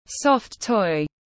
Thú nhồi bông tiếng anh gọi là soft toy, phiên âm tiếng anh đọc là /ˌstʌft ˈæn.ɪ.məl/
Soft toy /ˌstʌft ˈæn.ɪ.məl/
Soft-toy-.mp3